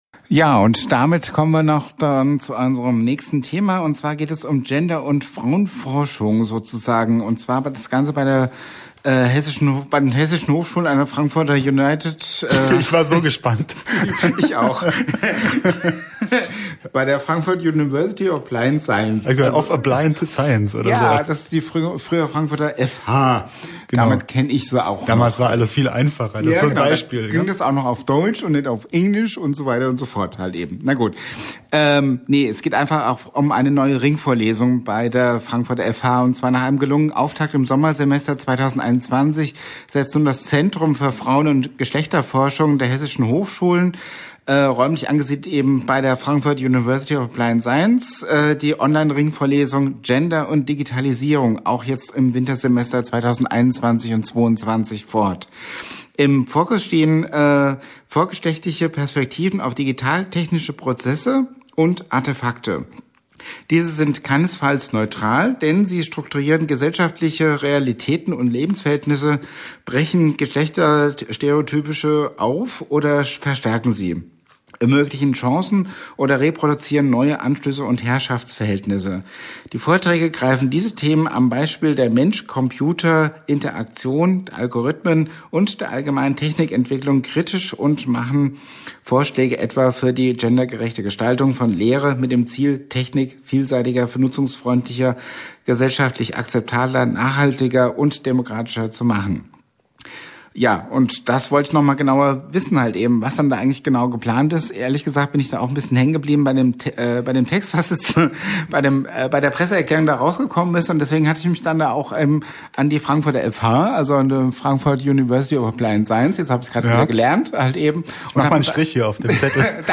Vorlesung an der FH Frankfurt
1266_fhvorlesung.mp3